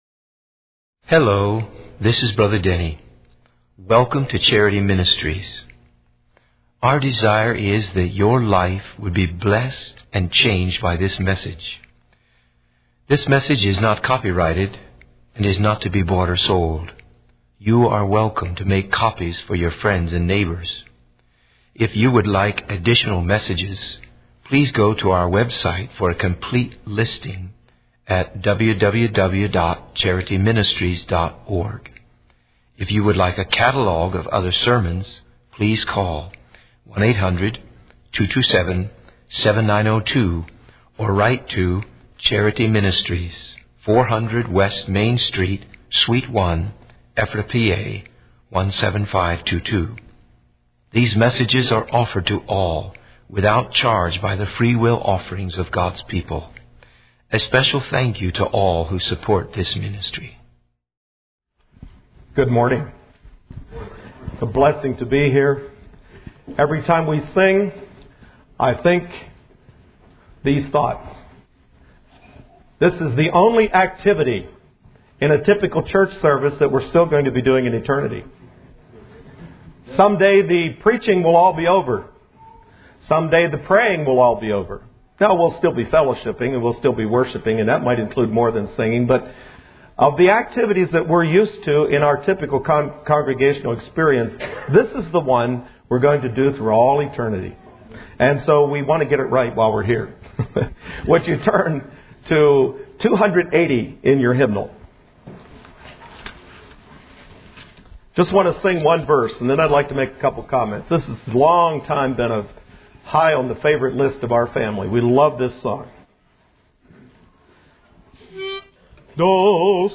In this sermon, the importance of music in worship is emphasized. The speaker recounts the story of Joshua and the Levites, who sang before going into battle and witnessed God's victory. The sermon also mentions the songs of Moses, which contained both praise and judgment.